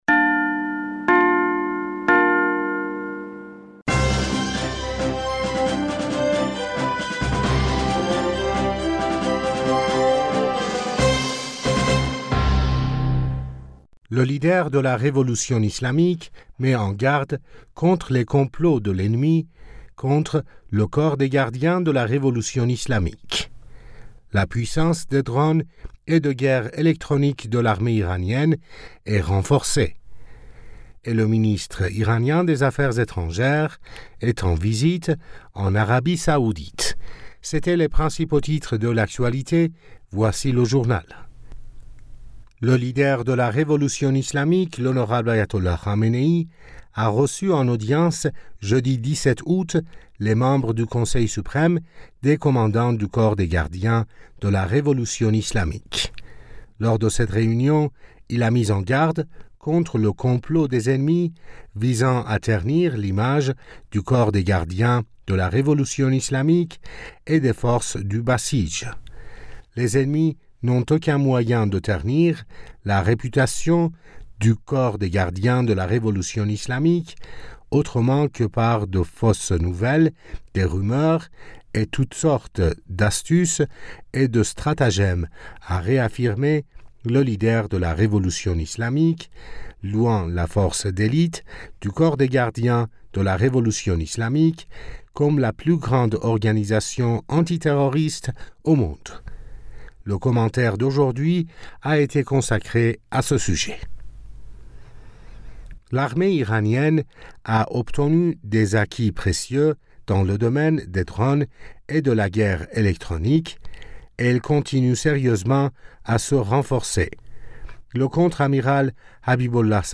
Bulletin d'information du 17 Aout 2023